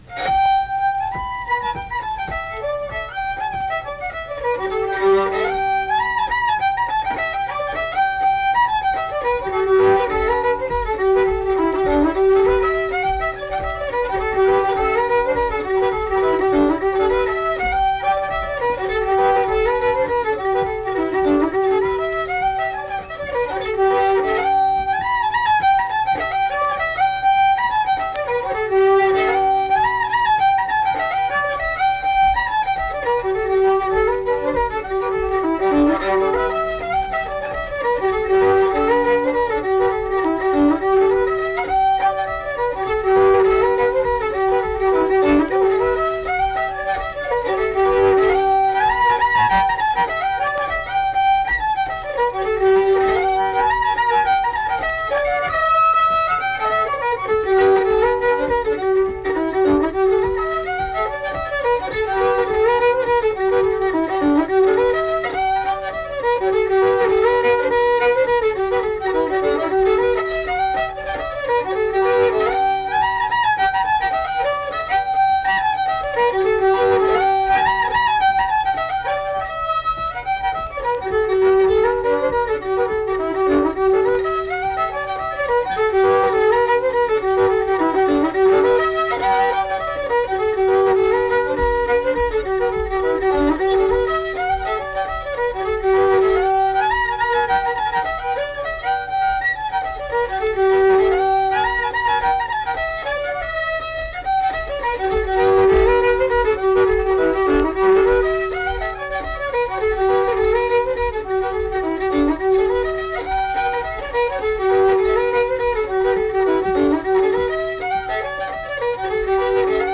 Accompanied guitar and unaccompanied fiddle music performance
Instrumental Music
Fiddle
Pocahontas County (W. Va.), Mill Point (W. Va.)